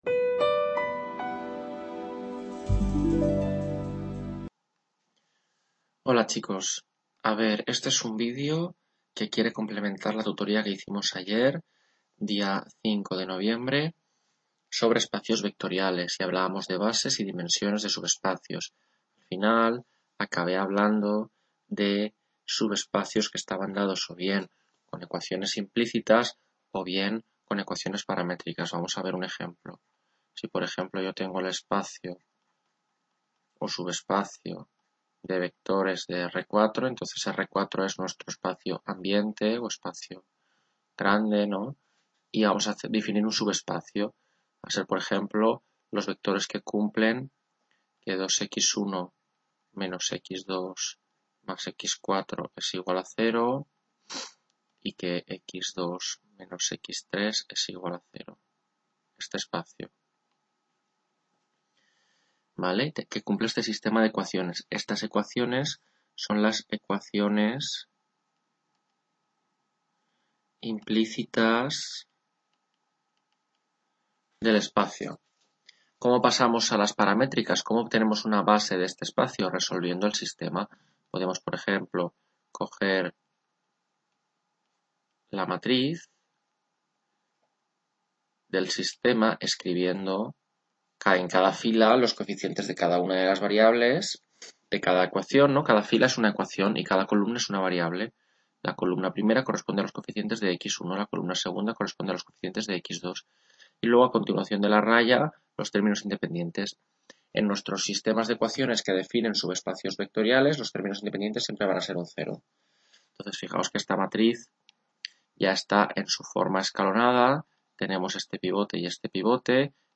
Tutoría 5 Álgebra - Subespacios, bases, dimensión (parte 2)